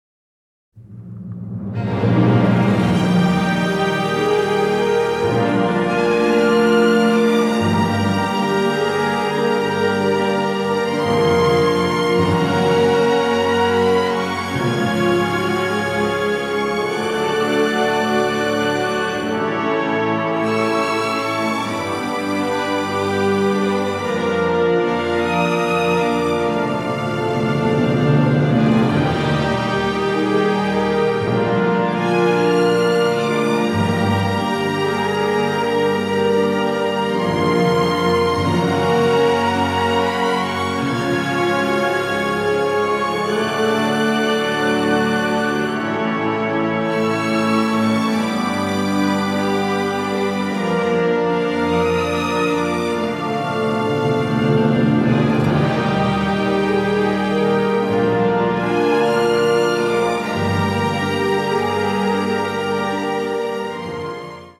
ORCHESTRA SUITE: